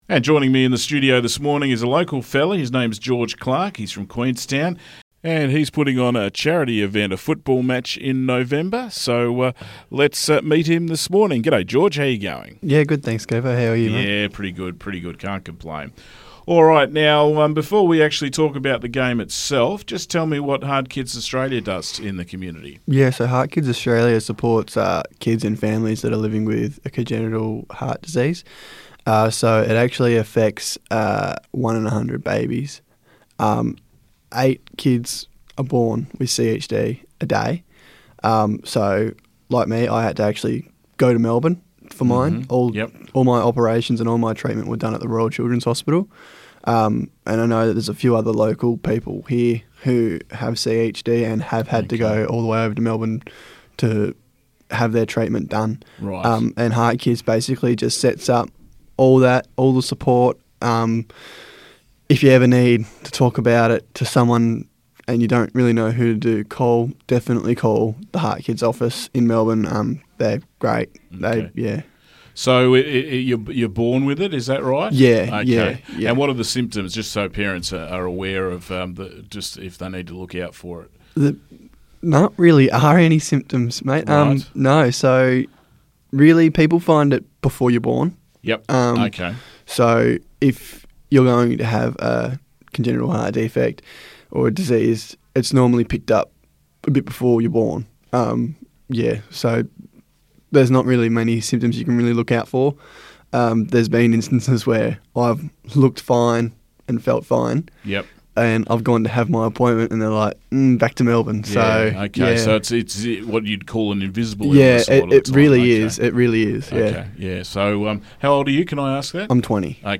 was joined in the studio